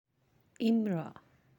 (imra’a)